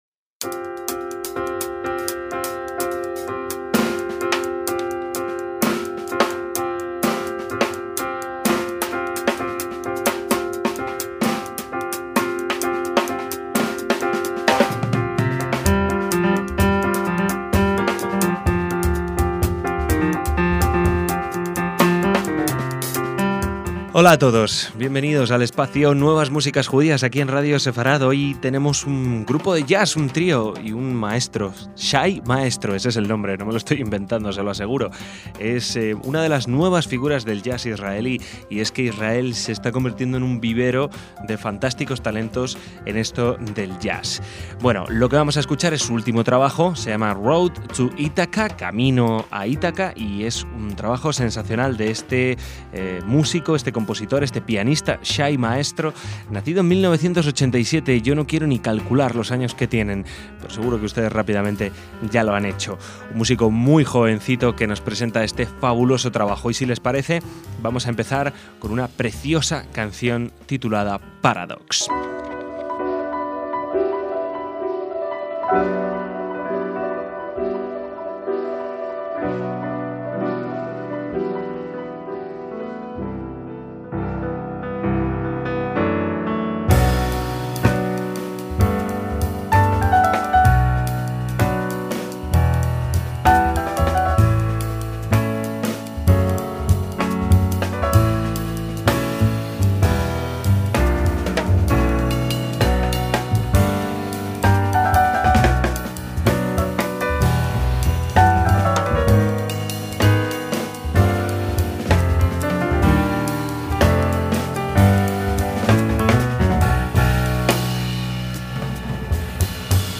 que debutó con su propio trío de jazz en 2011